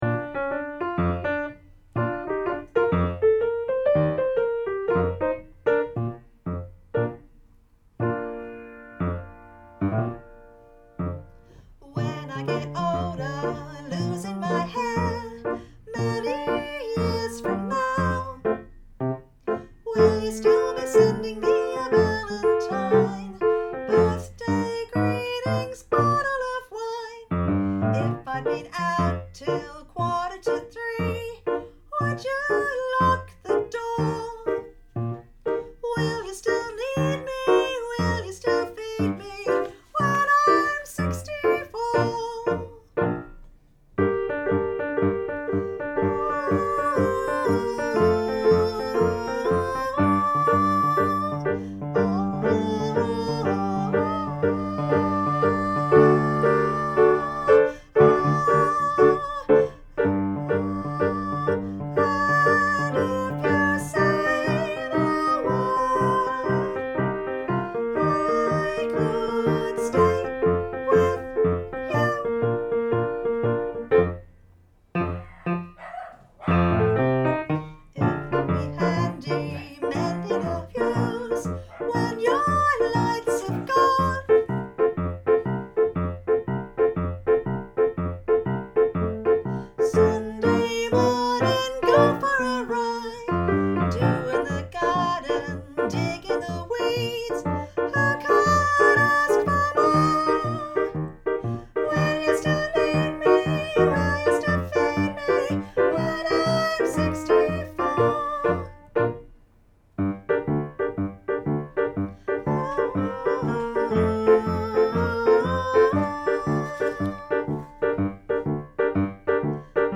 Vox Populi Choir is a community choir based in Carlton and open to all comers.
When_Im_64-tenor.mp3